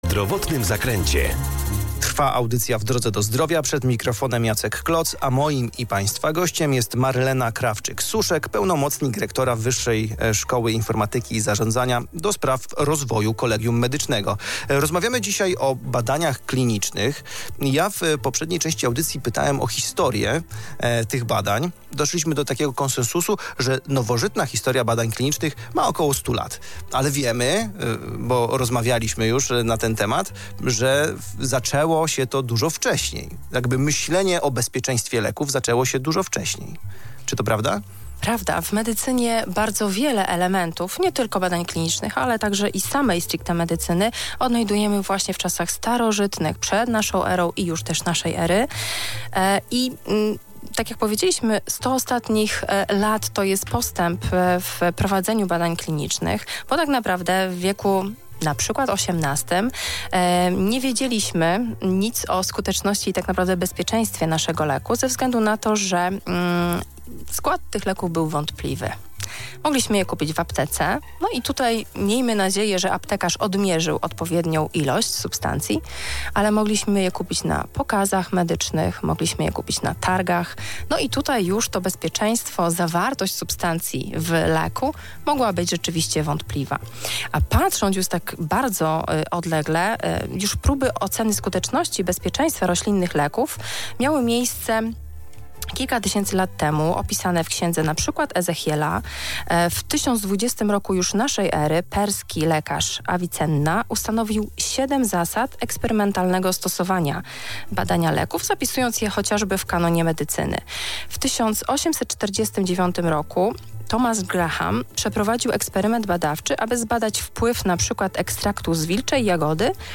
Zapis audycji pt. „W drodze do zdrowia” Radia VIA Rzeszów dostępny jest poniżej: